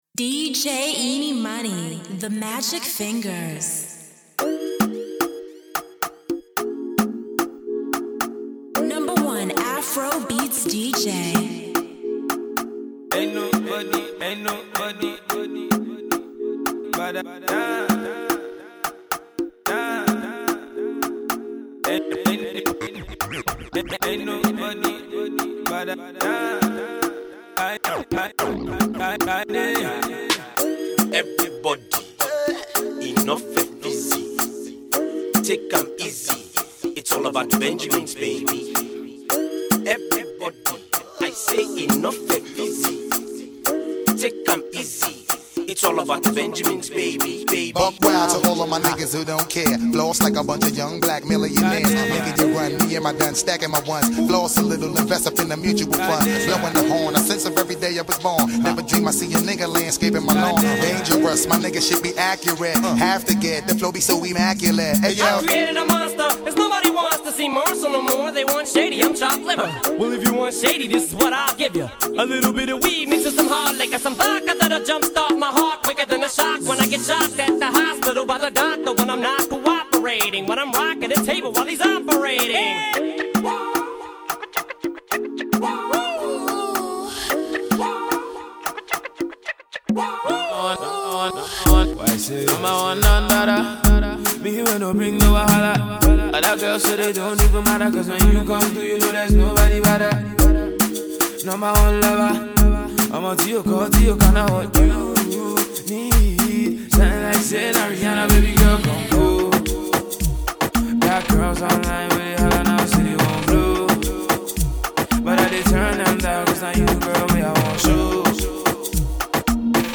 The joint is pretty much a mash-up